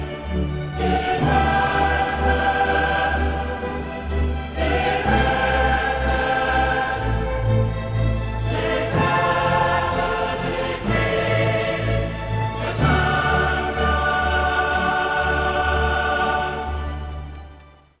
strømmer ut fra høyttalerne